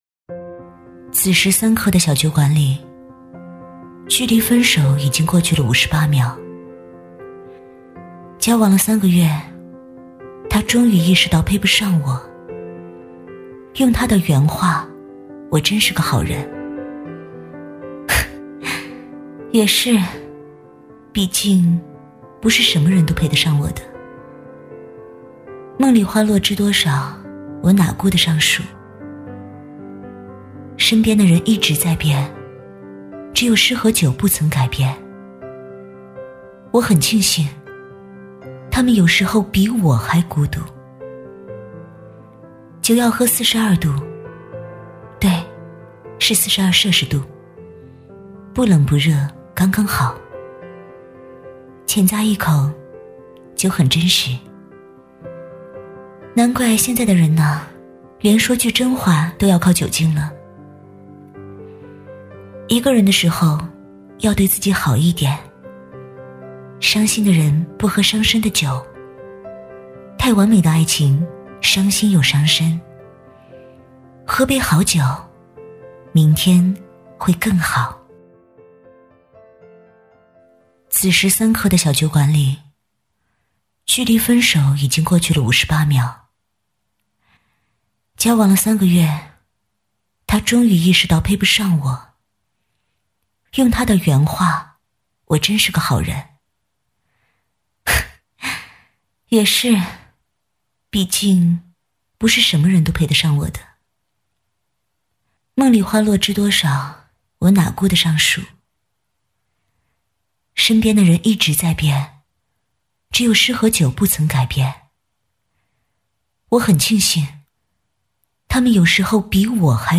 女配音-配音样音免费在线试听-第56页-深度配音网
女385温柔知性配音 v385